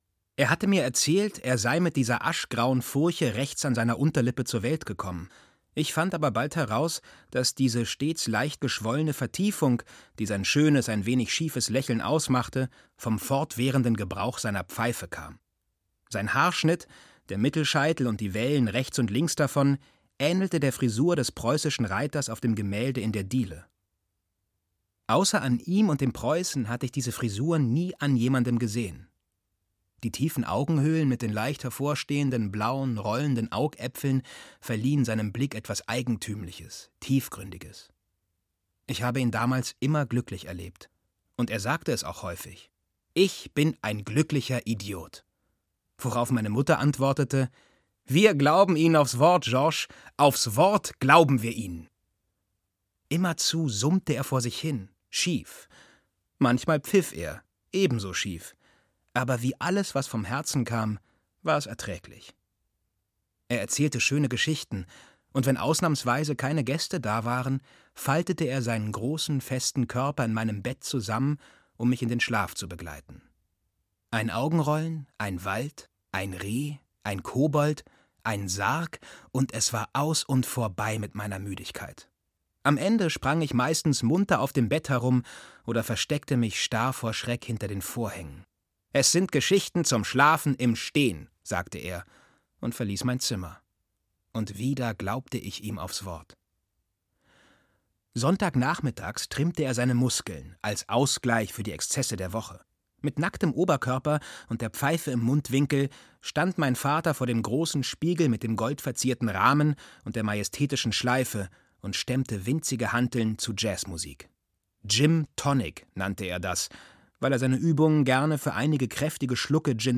Warten auf Bojangles - Olivier Bourdeaut - Hörbuch